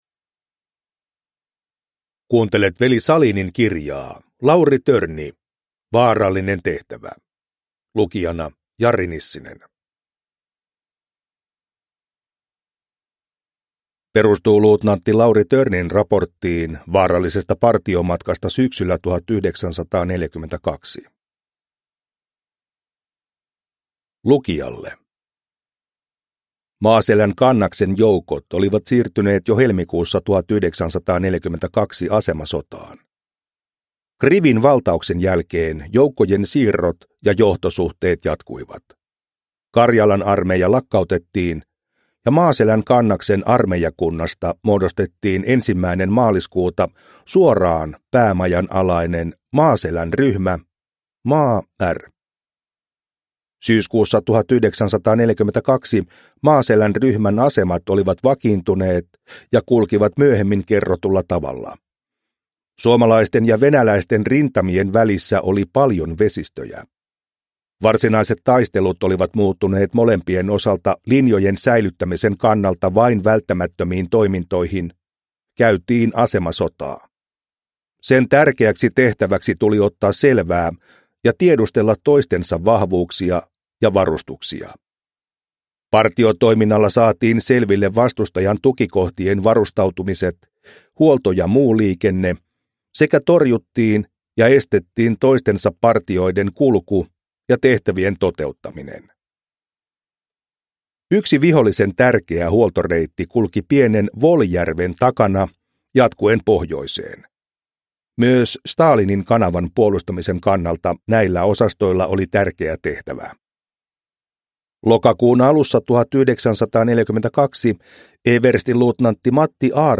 Lauri Törni - Vaarallinen tehtävä – Ljudbok – Laddas ner